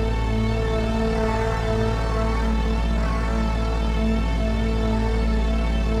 Index of /musicradar/dystopian-drone-samples/Non Tempo Loops
DD_LoopDrone2-G.wav